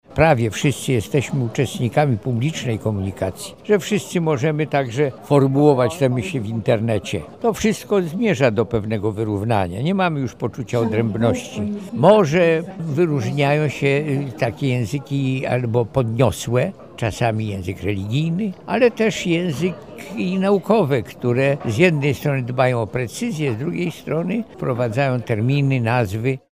Współczesne środki przekazu powodują, że niemal wszyscy mogą zabierać głos publicznie. Ta możliwość ma duży wpływ na ewolucję języka – dodał profesor Bralczyk.